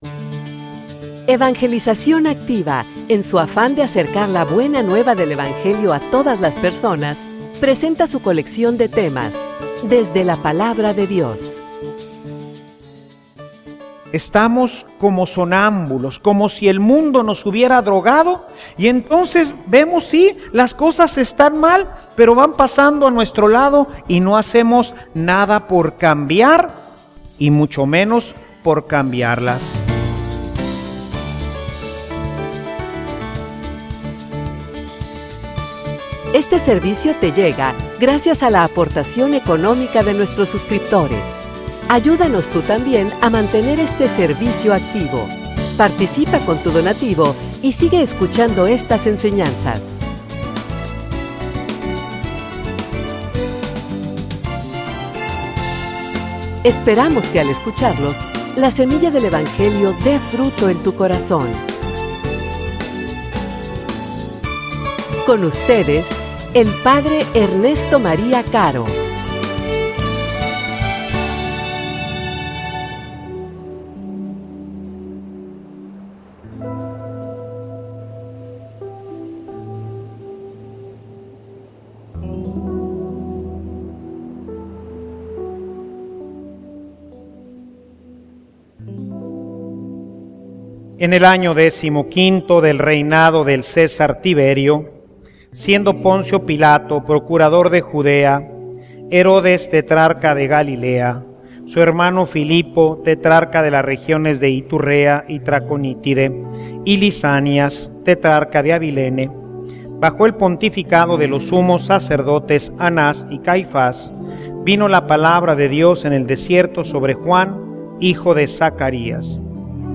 homilia_Despierta_tu_que_duermes.mp3